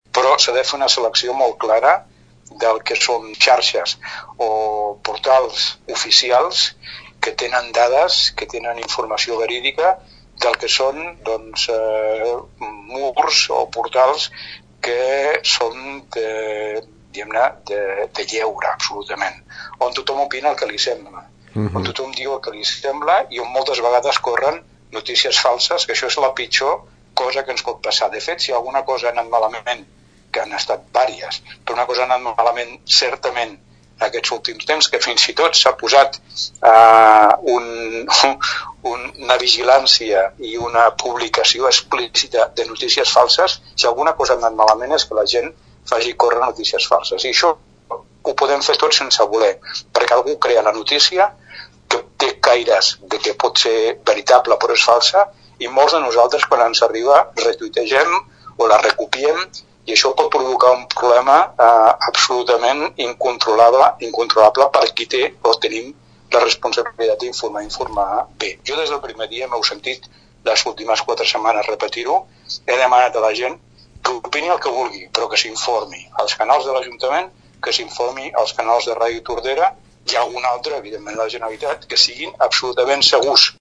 També es demana que no es faci circular informacions que no estiguin contrastades i que no provinguin de fonts oficials, mantenint una actitud de distanciament social sempre que sigui possible i respectant els confinaments i les indicacions dels professionals sanitaris. Escoltem l’Alcalde de Tordera, Joan Carles Garcia.
alcalde-canals-oficials.mp3